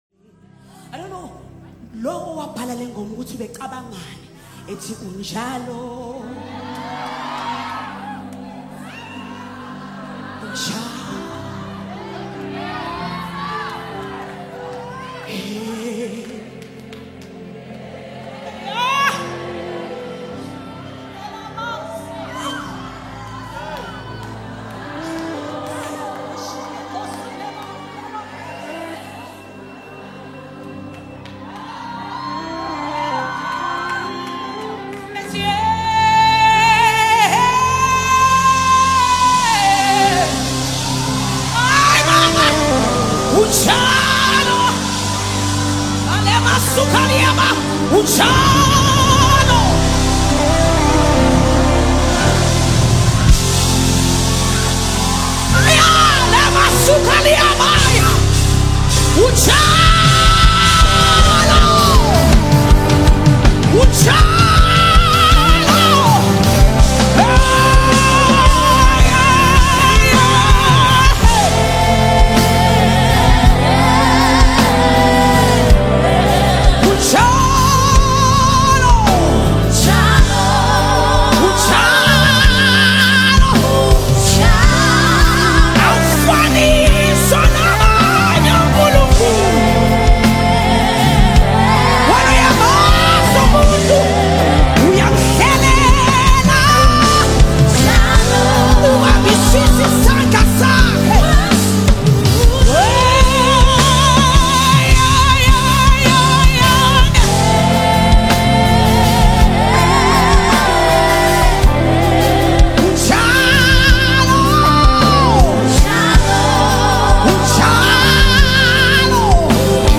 Anointed vocals and heartfelt worship
📅 Category: South African Deep Worship Song